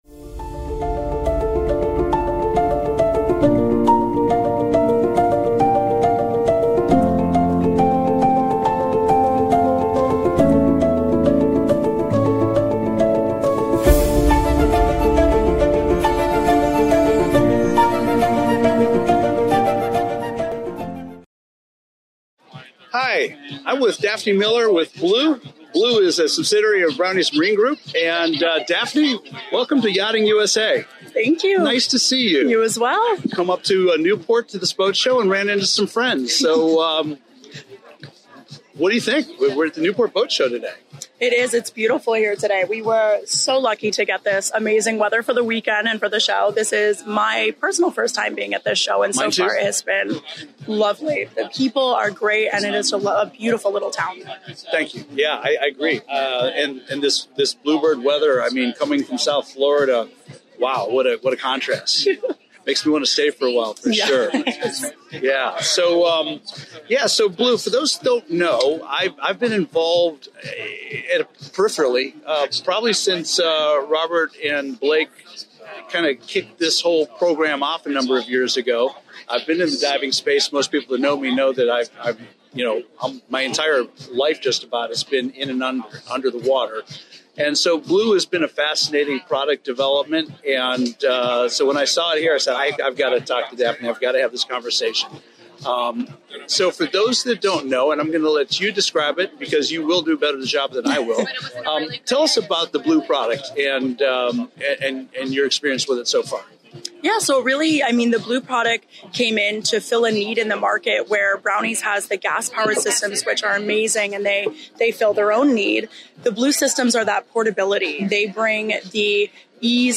live from the Newport International Boat Show!
In this interview, we explore the evolution of BLU3’s innovative products, the ease of diving without heavy tanks, and how this technology bridges the gap for non-certified divers.